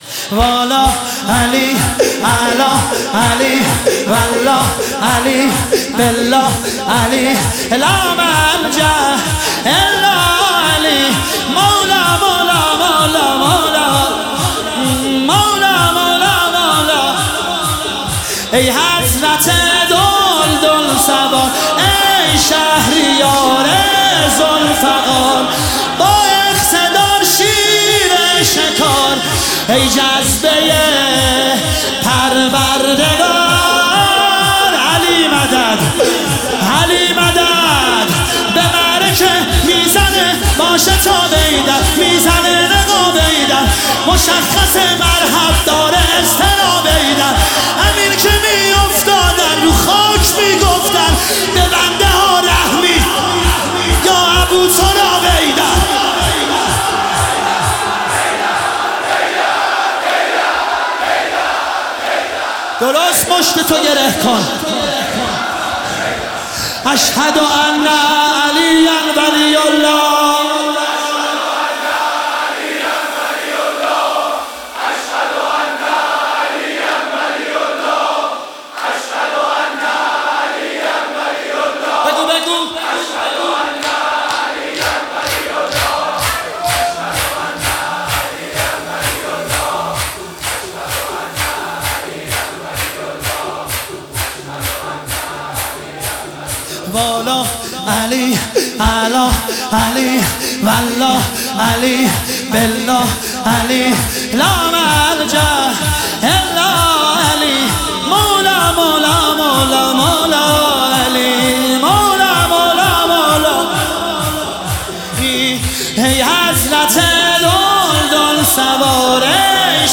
مداحی شور شب 23 ماه رمضان شب قدر